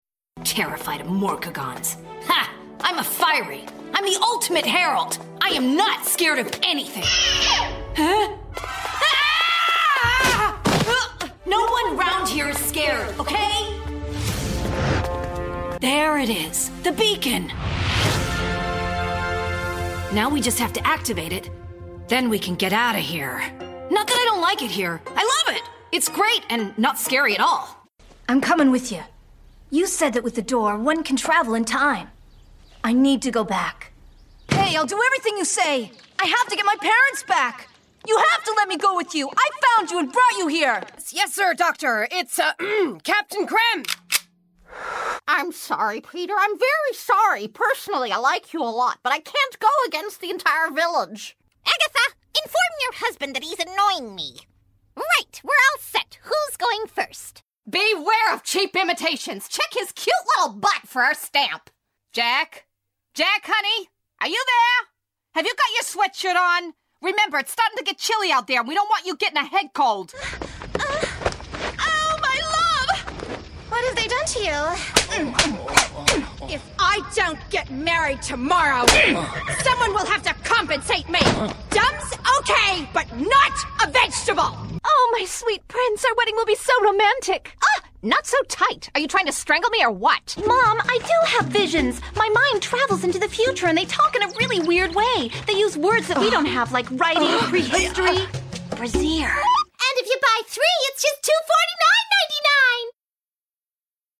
Animation Demo